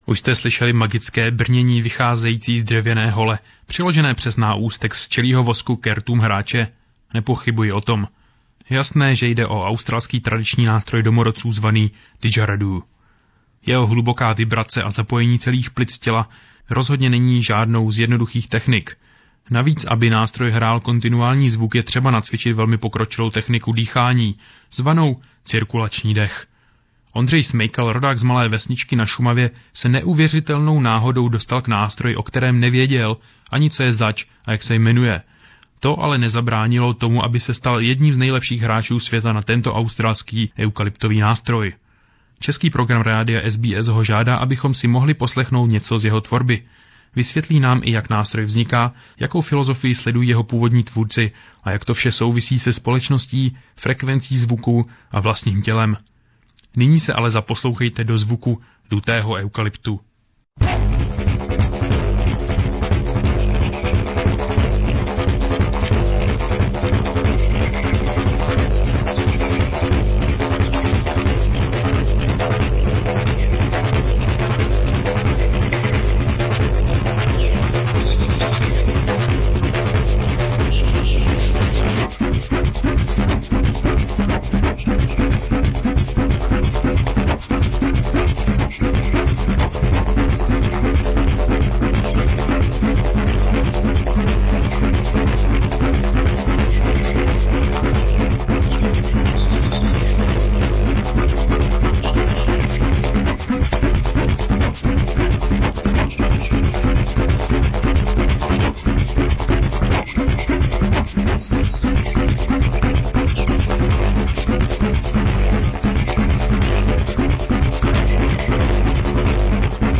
Didgeridoo
Didgeridoo is a truly amazing traditional music instrument. It combines a huge variety of sounds and articulating techniques.